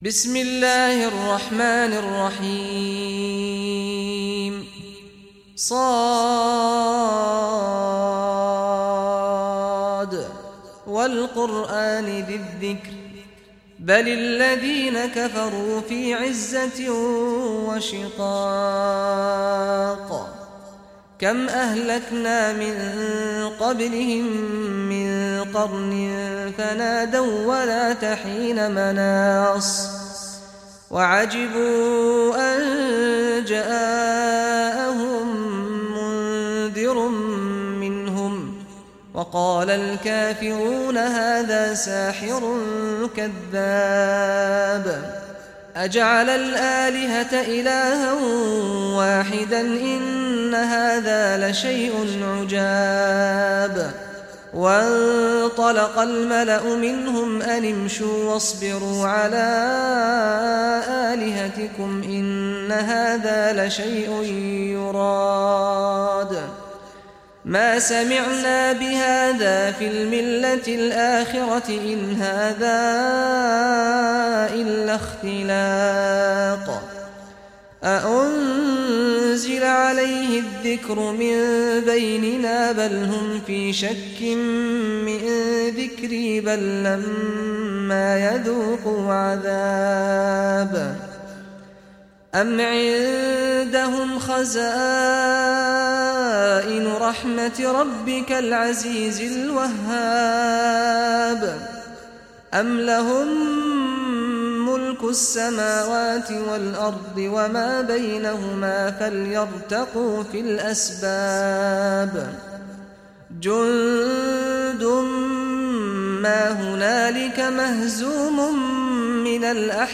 Surah Sad Recitation by Sheikh Saad al Ghamdi
Surah Sad, listen or play online mp3 tilawat / recitation in Arabic in beautiful voice of Sheikh Saad al Ghamdi.